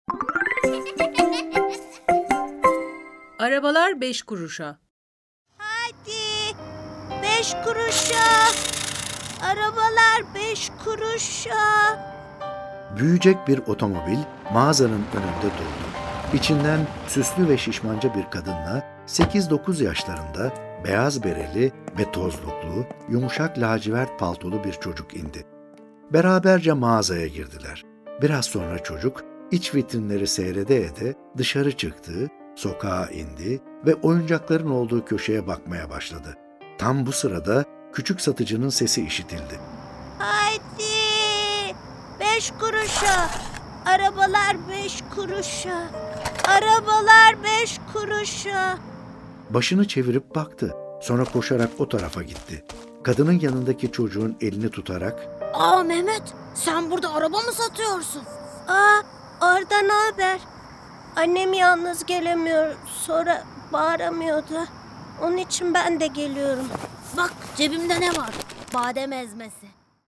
Arabalar Beş Kuruşa Tiyatrosu